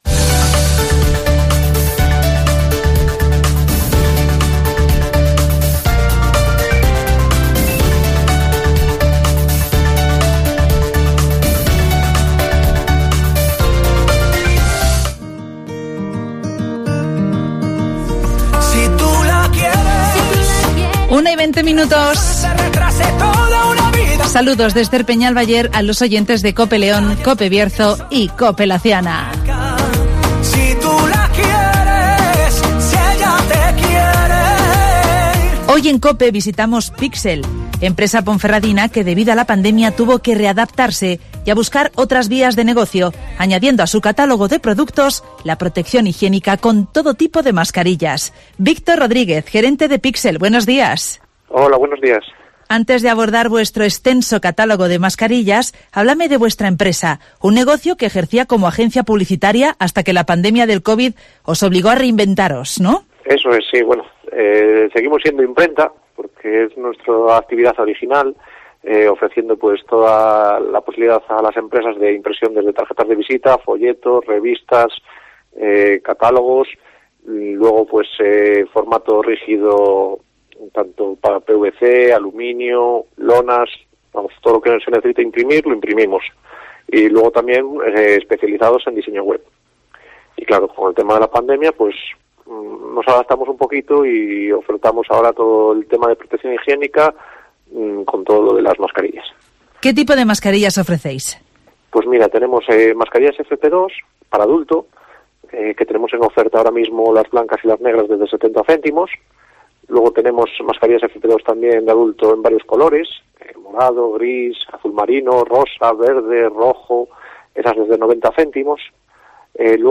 Pixel te ofrece una gran variedad de mascarillas FFP2 para adultos y niños a los mejores precios (Entrevista